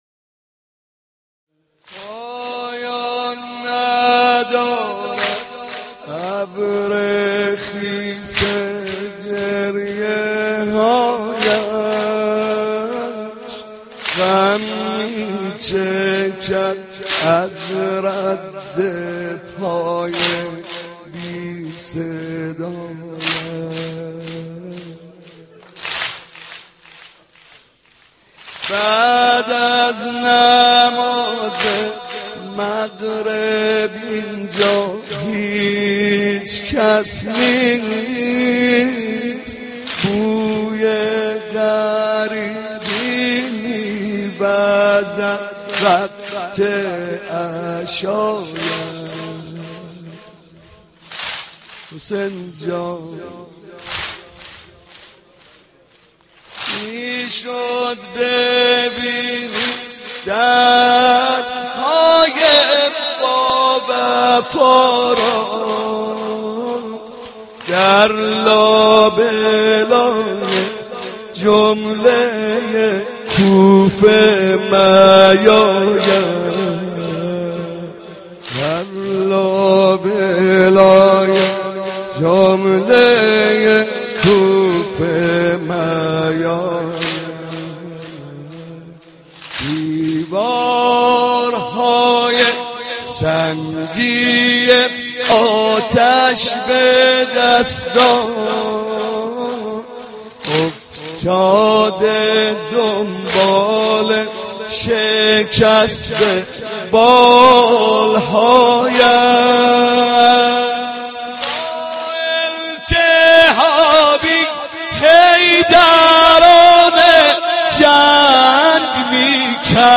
حاج محمدرضا طاهری / دانلود مداحی محرم ۱۳۸۵